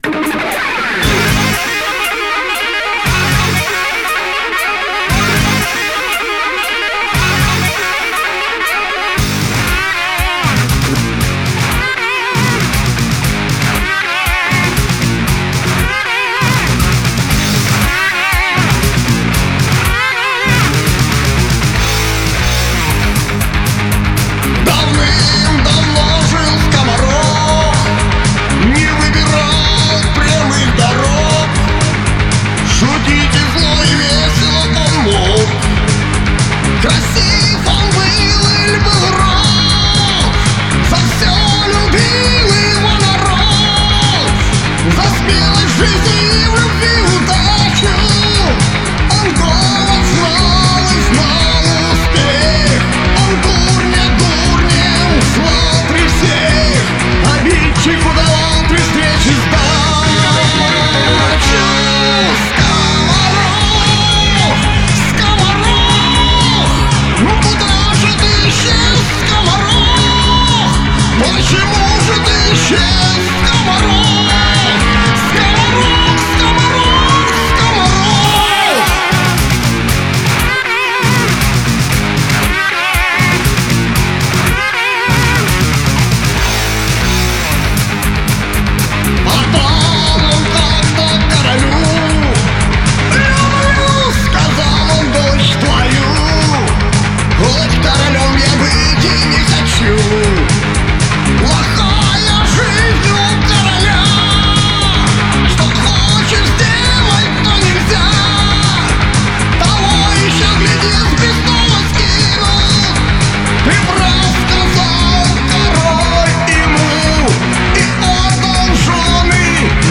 Мощные роковые песни и исполнение!